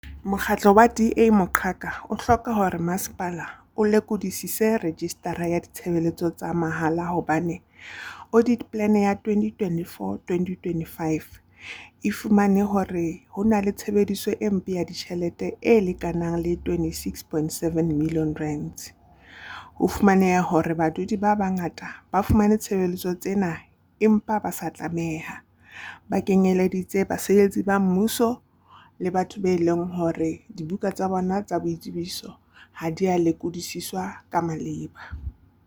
Sesotho soundbites by Cllr Palesa Mpele and